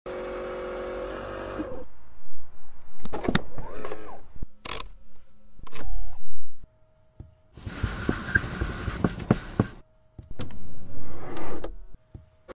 ( $232K) ASMR Tesla CyperBeast sound effects free download